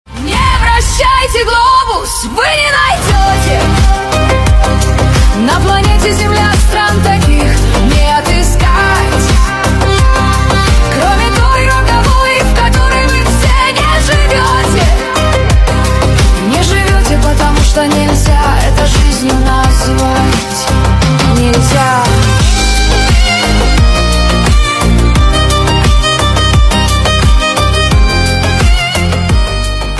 нейросеть , cover , скрипка , поп